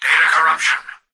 "Data corruption" excerpt of the reversed speech found in the Halo 3 Terminals.